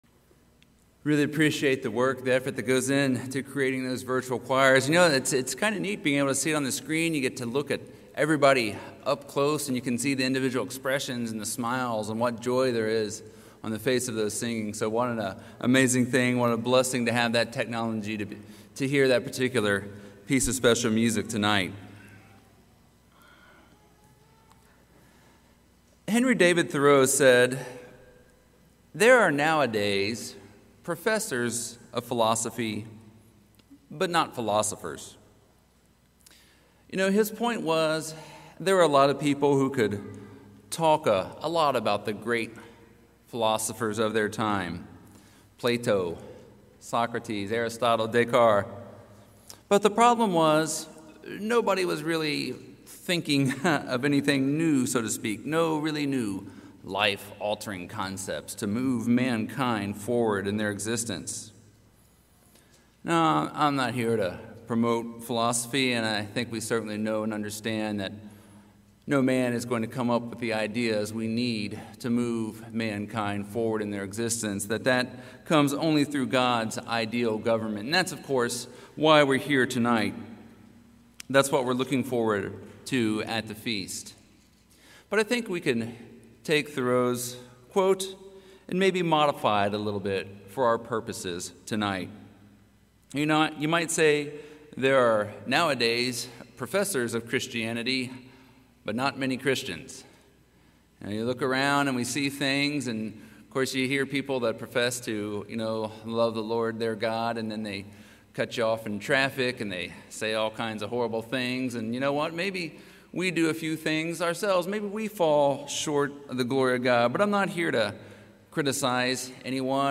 This sermon was given at the Panama City Beach, Florida 2020 Feast site.